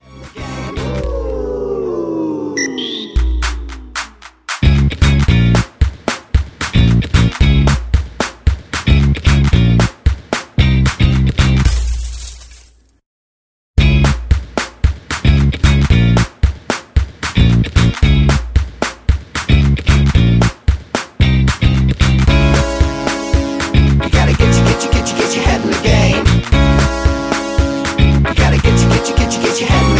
Dbm
MPEG 1 Layer 3 (Stereo)
Backing track Karaoke
Pop, Musical/Film/TV, 2000s